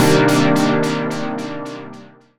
Synth Stab 19 (C).wav